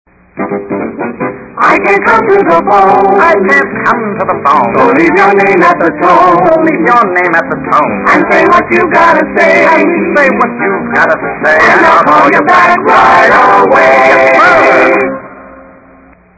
Vaudeville